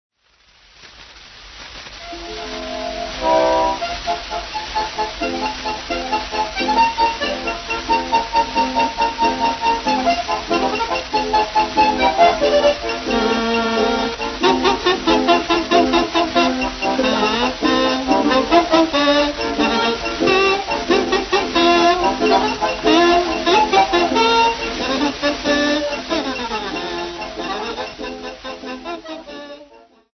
Folk Music
Field recordings
Africa Democratic Republic of Congo city not specified f-cg
sound recording-musical
Indigenous music